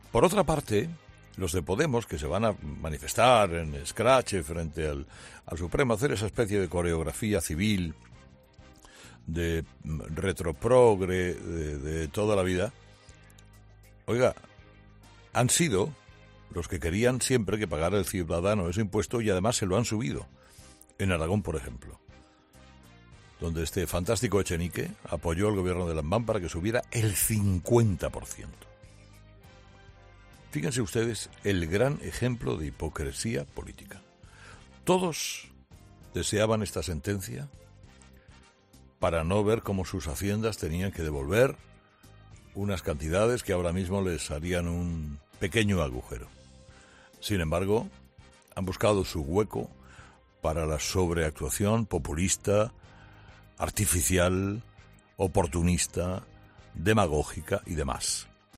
Estas contradicciones han despertado el interés de Carlos Herrera, que en su monólogo de este jueves ha criticado la hipocresía de Podemos.